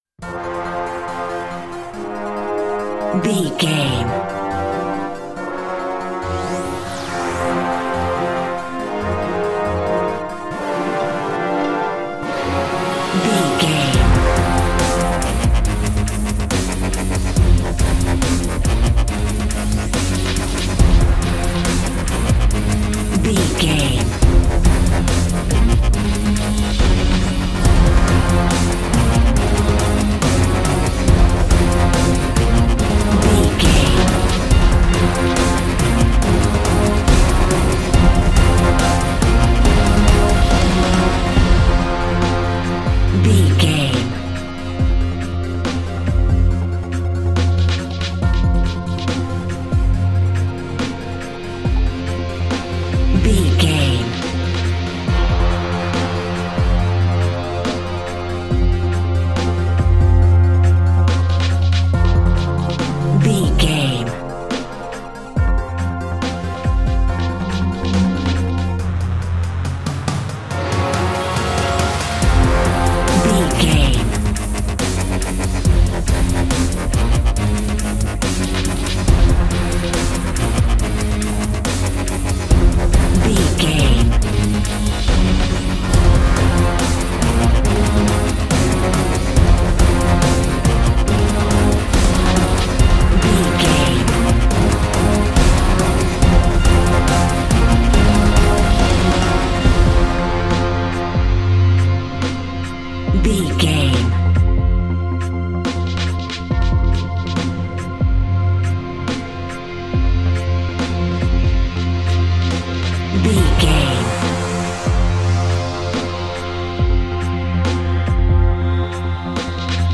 Epic / Action
Fast paced
In-crescendo
Dorian
synthesiser
drum machine
electric guitar
driving drum beat